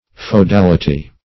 Search Result for " feodality" : The Collaborative International Dictionary of English v.0.48: Feodality \Feo*dal"i*ty\, n. Feudal tenure; the feudal system.